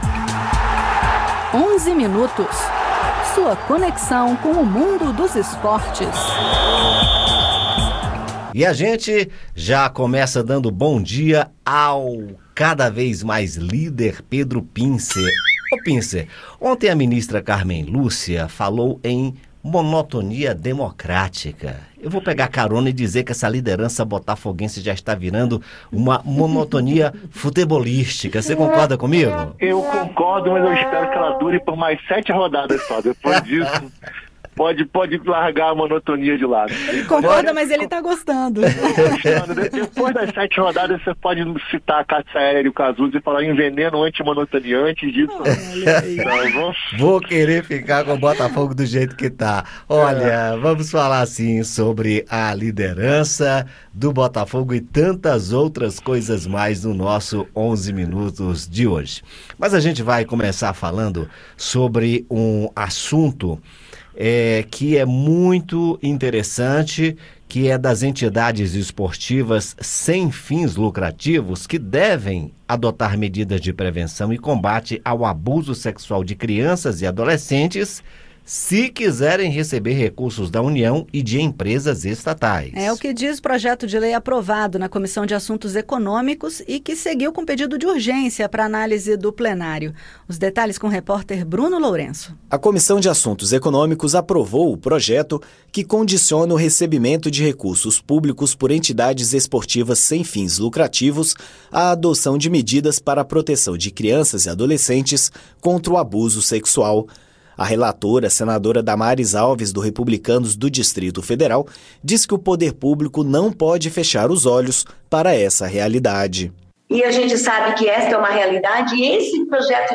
No Onze Minutos desta segunda-feira (28), nossos comentaristas falam sobre um projeto em análise no Senado que condiciona o recebimento de recursos públicos por entidades esportivas sem fins lucrativos à adoção de medidas para proteção de crianças e adolescentes contra o abuso sexual. Você vai ouvir também os comentários sobre os jogos da série A e da série B do Campeonato Brasileiro e sobre a expectativa para o troféu Bola de Ouro, que será entregue hoje em Paris, e que tem como favorito o jogador brasileiro Vini Jr.